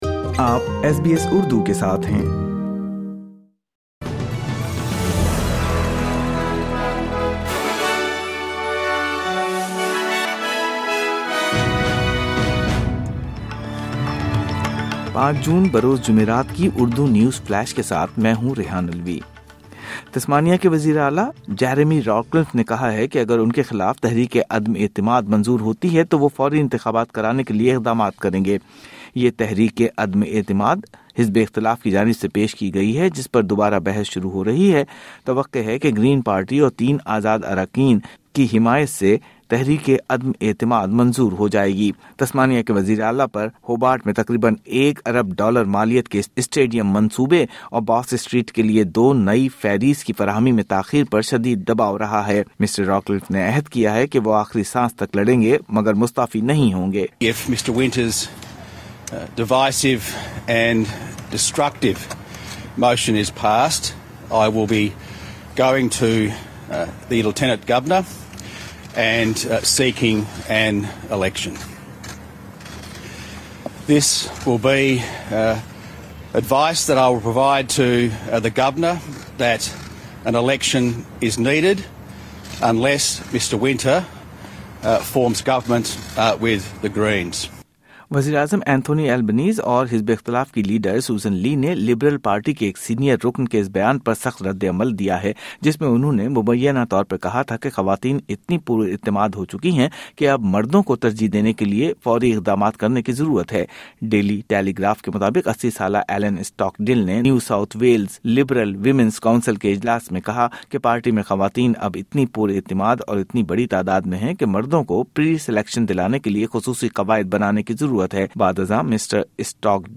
مختصر خبریں:جمعرات 6 جون 2025: امریکہ نے غزہ میں جنگ بندی کی قرارداد ویٹو کر دی